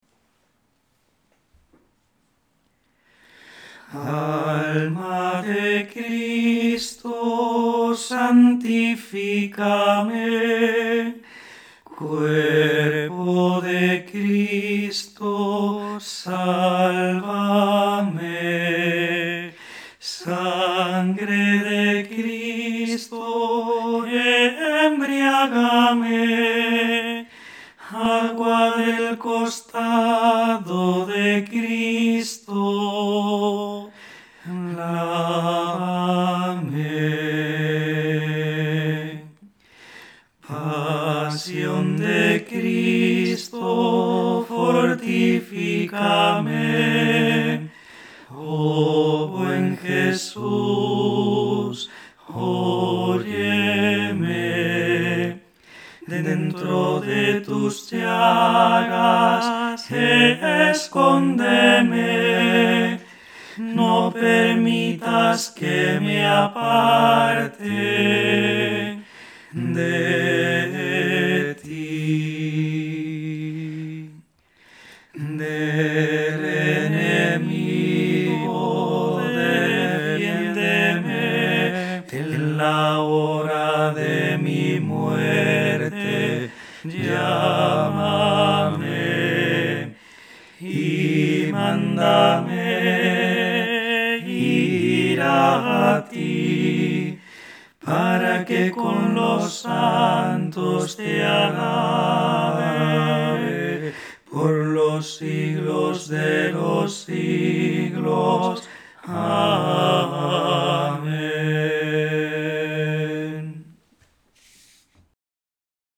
Música Litúrgica
Alma de Cristo A Capella